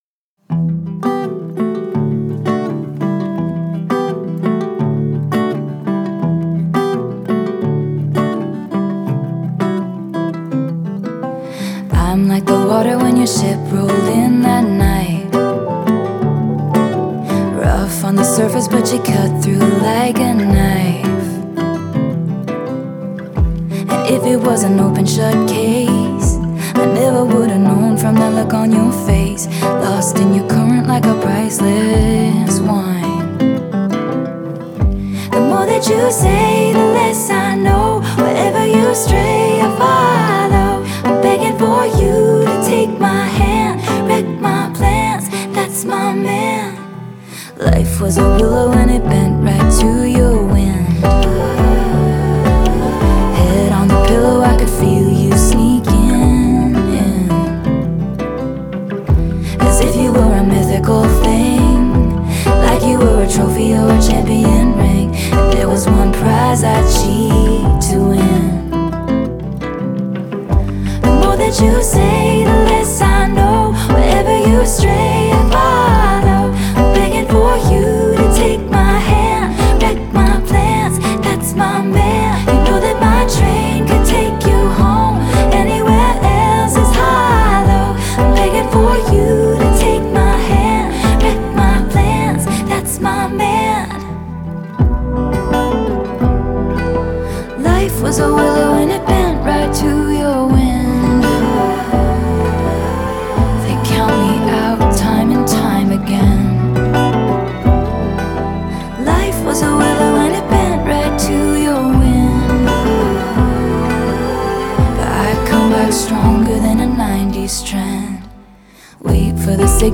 в жанре фолк-поп